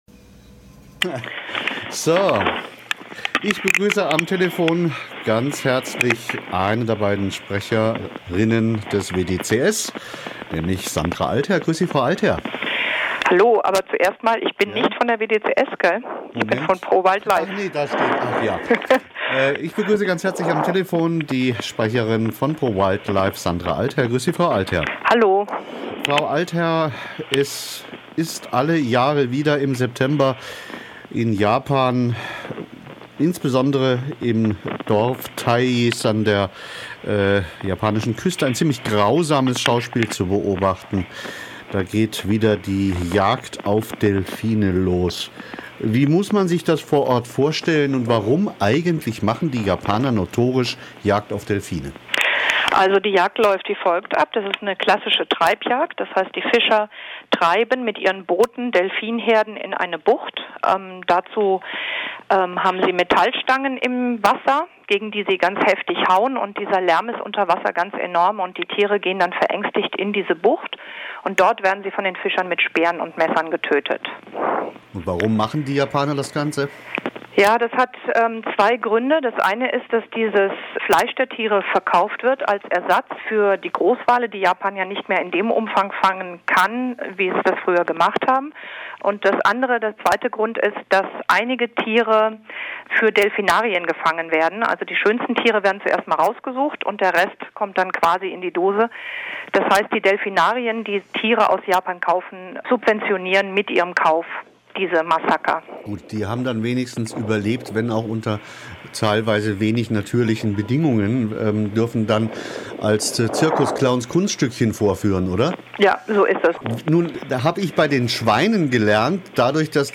Gitarre und Gesang
Bass
Schlagzeug) um den Rock ‘n‘ Roll in der Provinz.